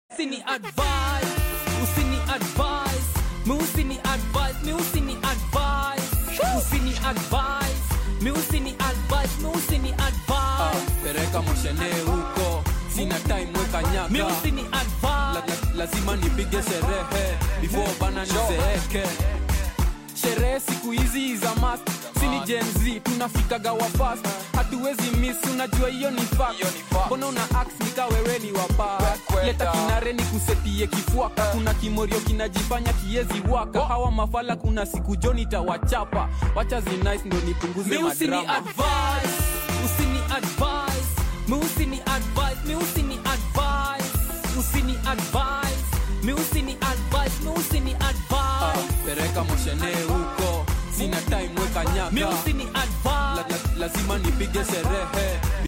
Canon EOS 2000D DSLR Camera sound effects free download